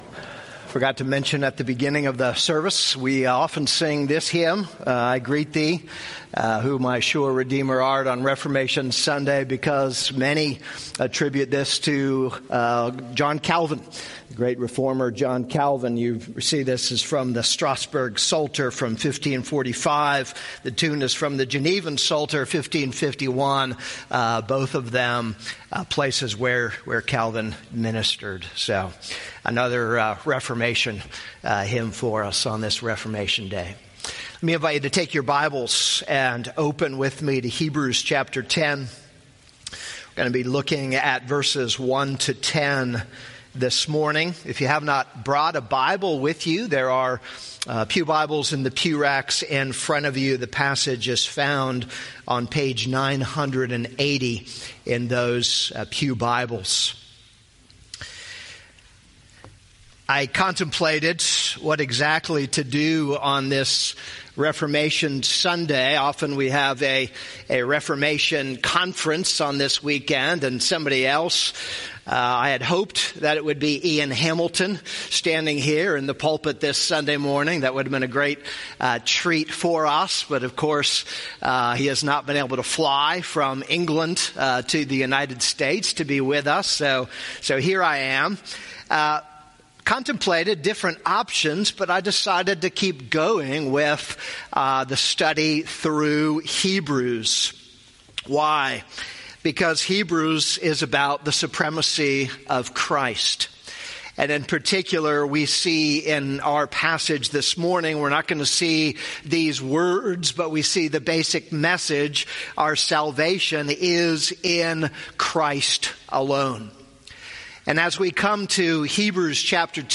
This is a sermon on Hebrews 10:1-10.